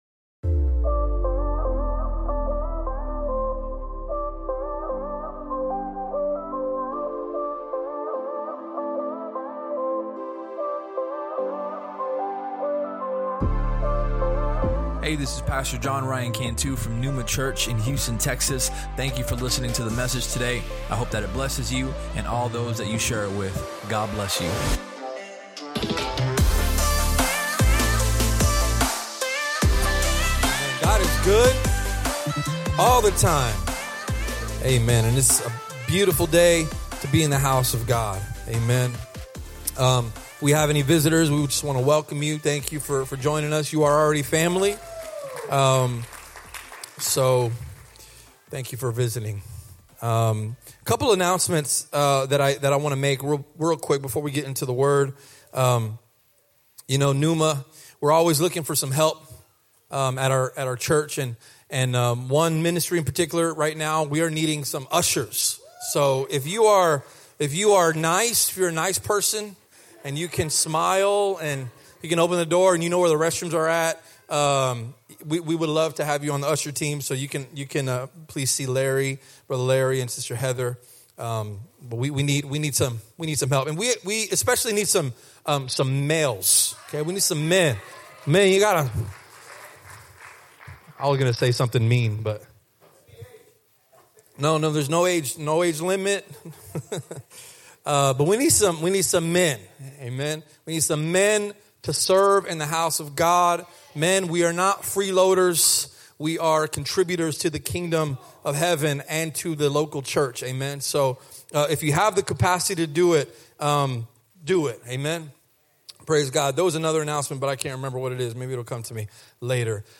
Sermon Topics: Unity, Expectations, Perspective If you enjoyed the podcast, please subscribe and share it with your friends on social media.